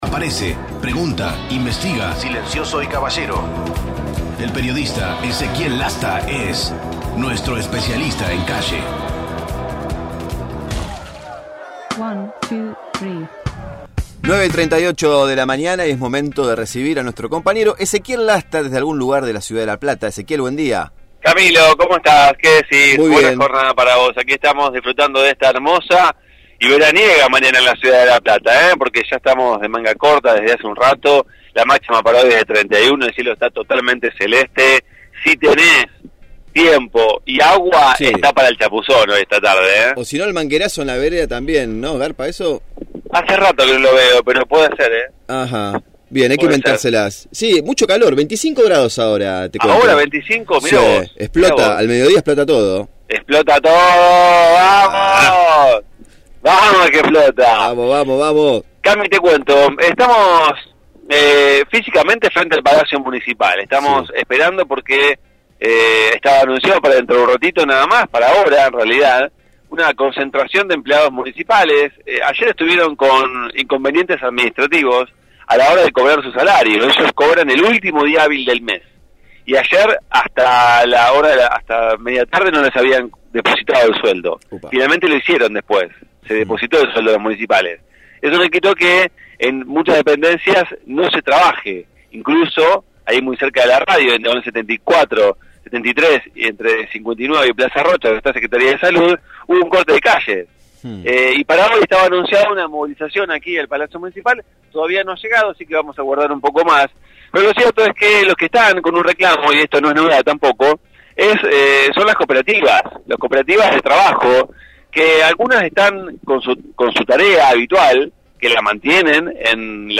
MÓVIL/ Reclamo de cooperativistas del Municipio
Integrantes de las cooperativas de trabajo dependientes de la comuna se concentraron en la puerta del Palacio Municipal para reclamar por la continuidad de los puestos de trabajo, ya que sus contratos vencen el 10 de diciembre, día en el que se realizará el traspaso de mando entre Pablo Bruera, intendente saliente, y Julio Garro, electo en los comicios del 25 de octubre.